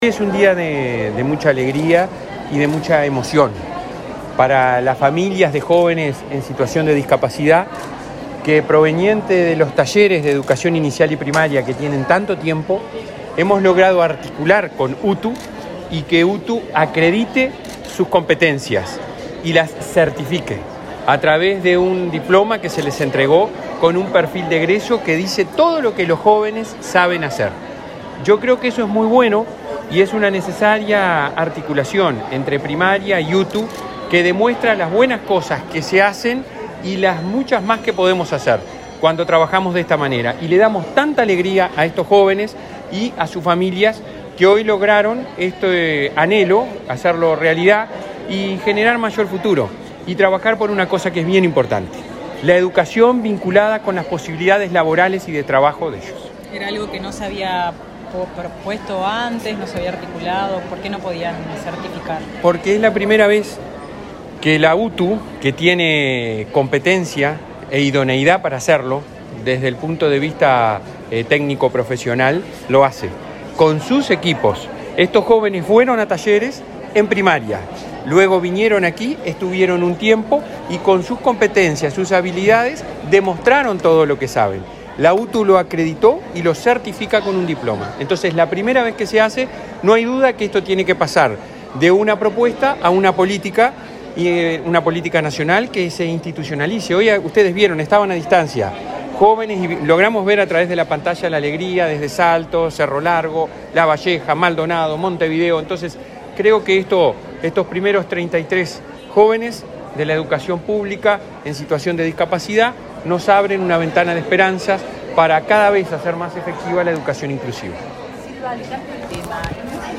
Declaraciones del presidente de la ANEP a la prensa
Declaraciones del presidente de la ANEP a la prensa 13/12/2022 Compartir Facebook X Copiar enlace WhatsApp LinkedIn Este martes 13, en Montevideo, el director general de la UTU, Juan Pereyra, y el presidente de la ANEP, Robert Silva, participaron en la entrega de certificados de acreditación de saberes a 34 jóvenes con discapacidad intelectual. Luego Silva dialogó con la prensa.